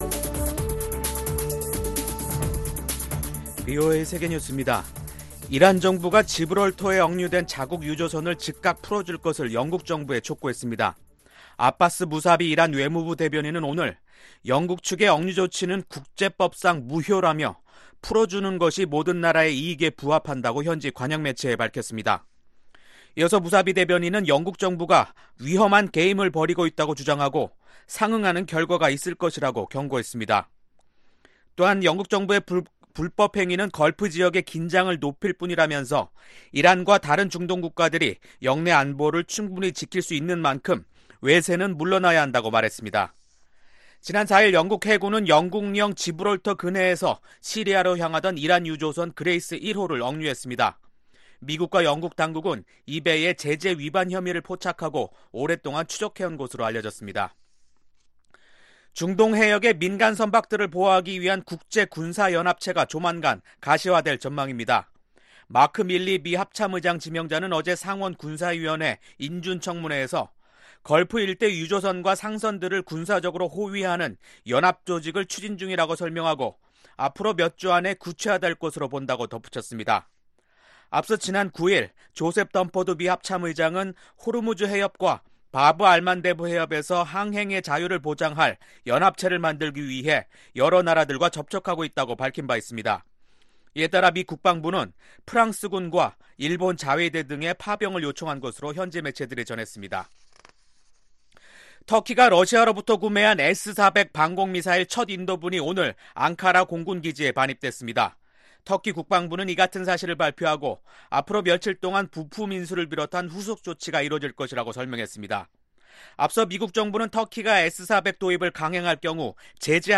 VOA 한국어 간판 뉴스 프로그램 '뉴스 투데이', 2019년 7월 12일 2부 방송입니다. 미 국무부는 미국이 북한의 핵 프로그램 동결을 조건으로 석탄과 섬유 수출 제재를 일부 유예하는 방안을 검토 중이라는 일부 언론 보도 내용을 부인했습니다. 유엔군사령부는 한반도 위기시 일본을 전력제공국으로 고려하고 있다는 언론 보도가 사실이 아니라고 밝혔습니다.